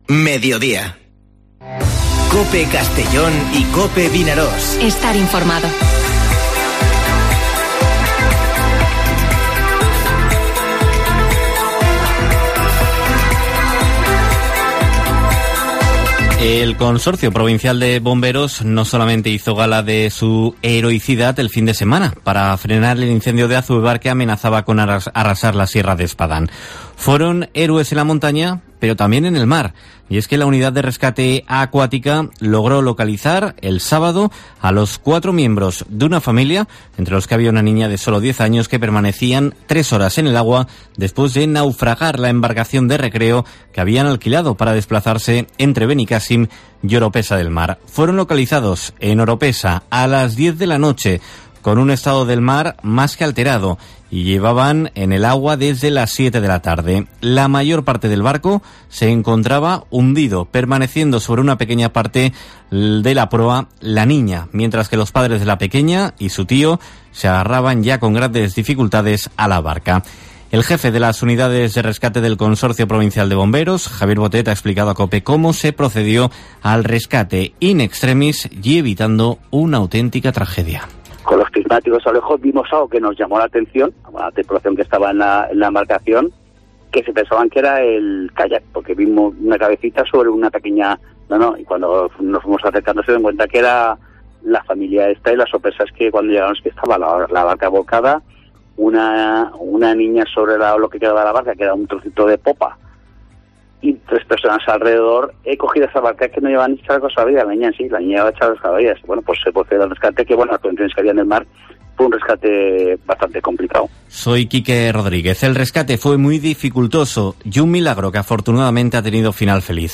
Informativo Mediodía COPE en la provincia de Castellón (18/08/2021)